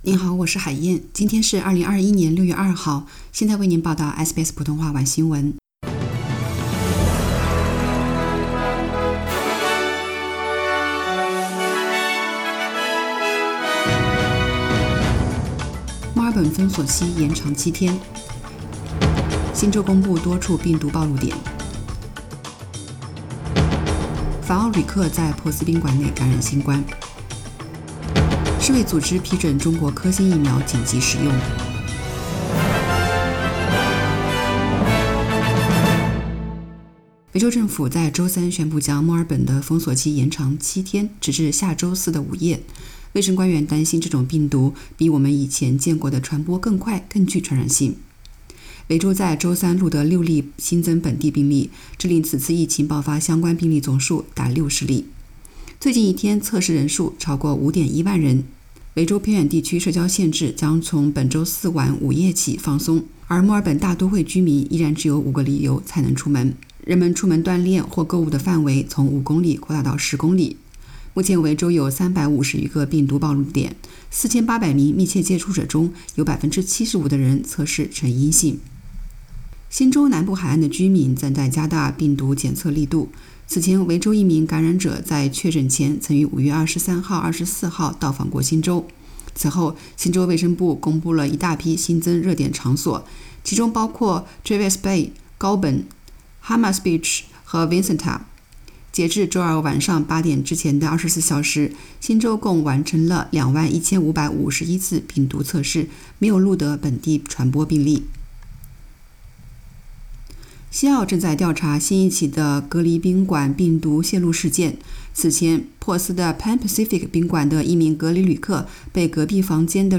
SBS Mandarin evening news Source: Getty Images